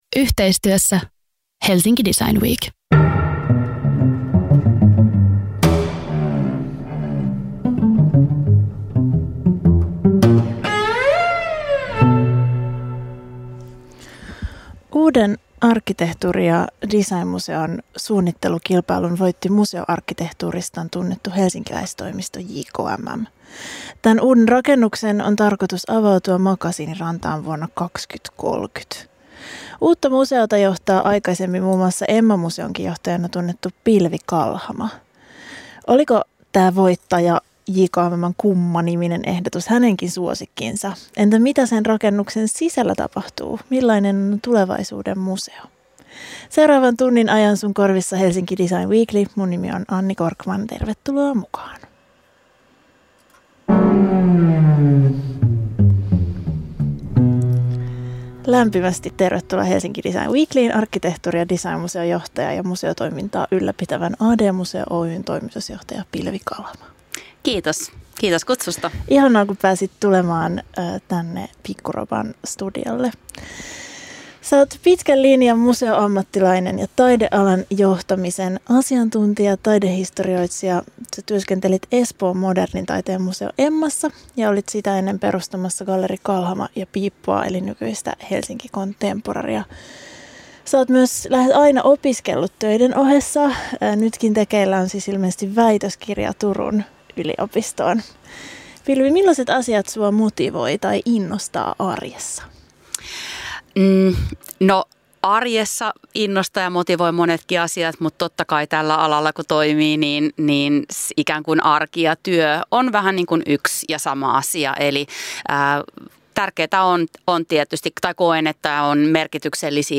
Helsinki Design Weekly käsittelee kaupunkiamme designlasien läpi ja tuo studioon muun muassa ajankohtaisia muotoilijoita, graafikoita ja arkkitehtejä.